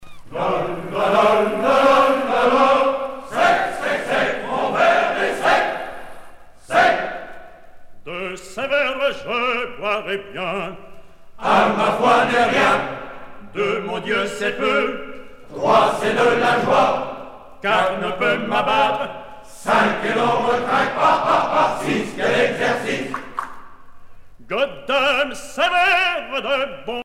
Ensemble choral
Pièce musicale éditée